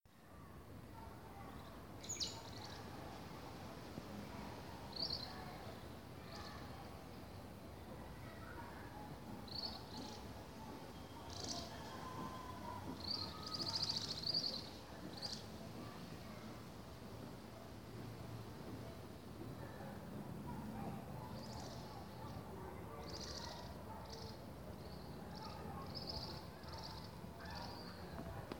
Southern Beardless Tyrannulet (Camptostoma obsoletum)
Life Stage: Adult
Location or protected area: Gran Buenos Aires Norte
Condition: Wild
Certainty: Observed, Recorded vocal
Piojito-Silbon-Call-EDIT.mp3